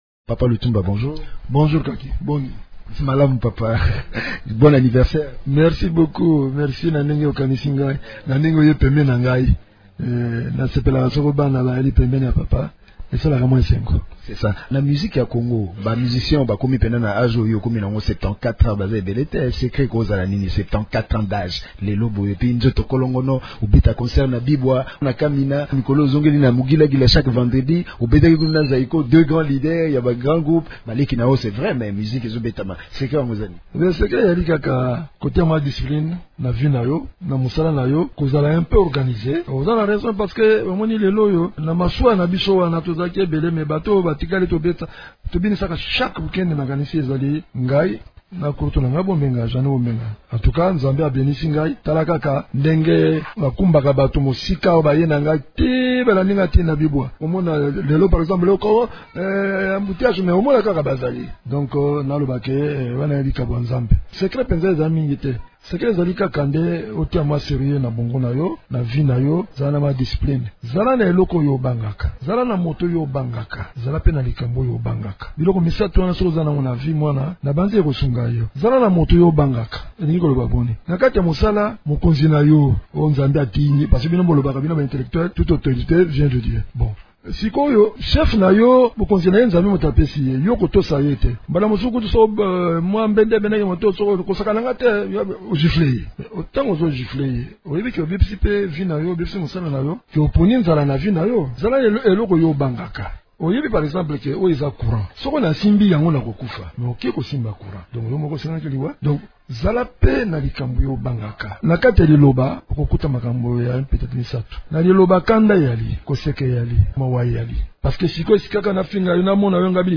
Lutumba Simaro compte cette année plus de cinquante ans de carrière musicale. L’artiste fait un petit bilan de son parcours, renseigne sur l’évolution des travaux d’enregistrement de son nouvel album et parle de la musique congolaise dans cet entretien